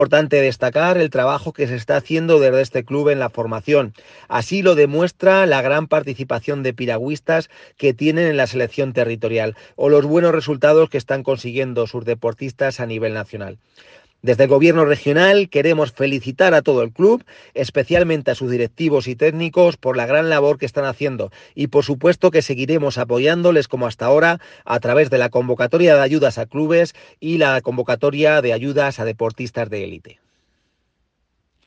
CORTE DIRECTOR GENERAL DE DEPORTES, CARLOS YUSTE